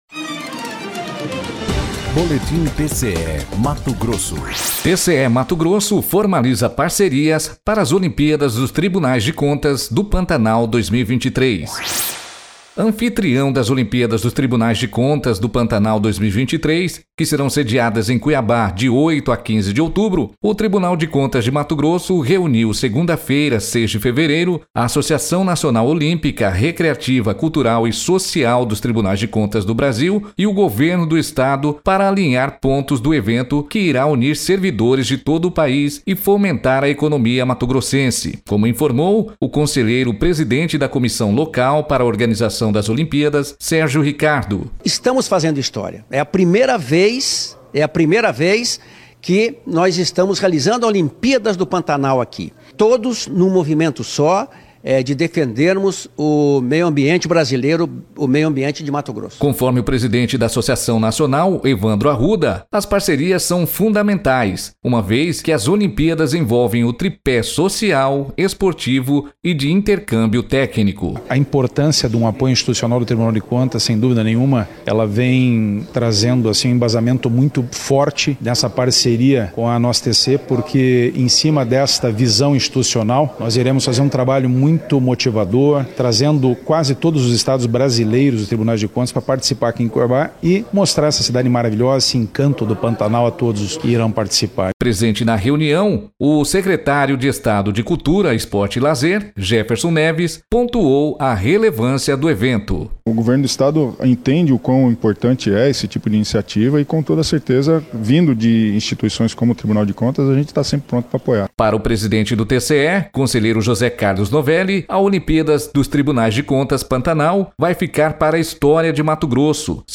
Sonora: Sérgio Ricardo - conselheiro presidente da Comissão Local para Organização das Olimpíadas
Sonora: Jefferson Neves - secretário de Estado de Cultura, Esporte e Lazer